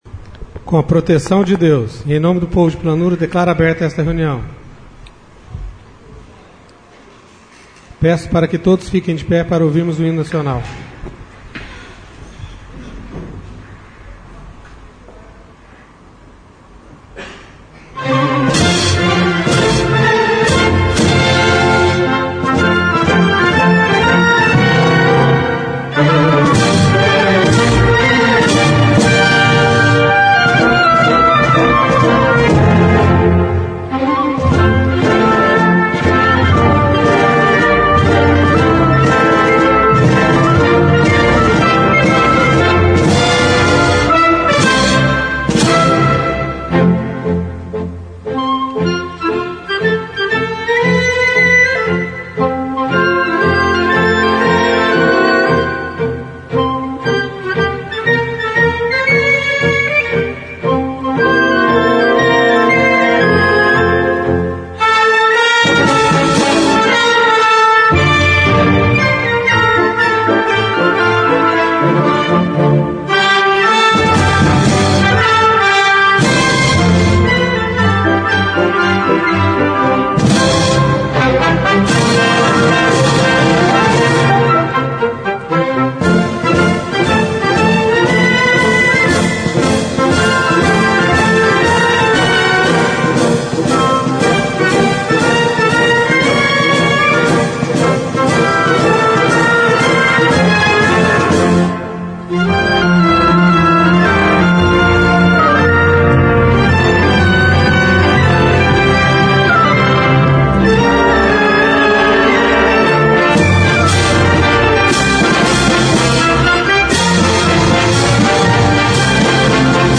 Sessão Ordinária - 04/08/14